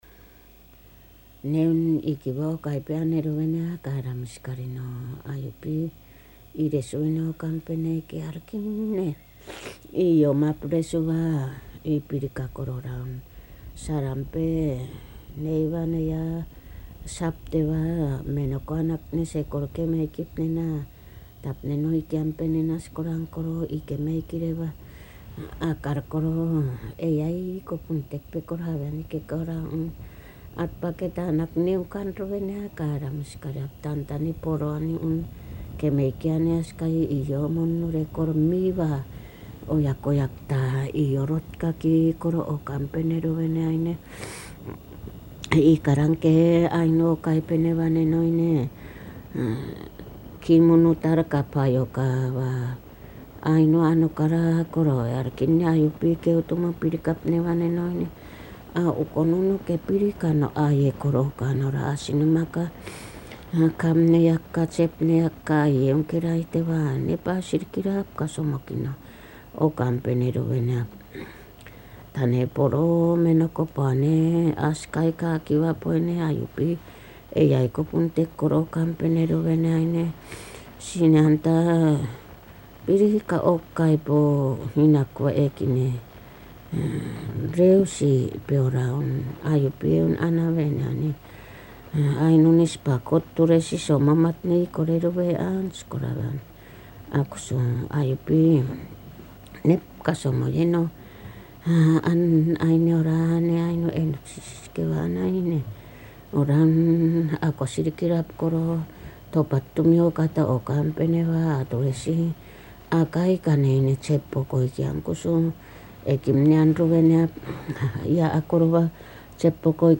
[4-10 散文説話 prose tales] アイヌ語音声 10:48